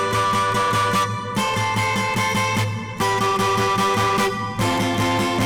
guitar.wav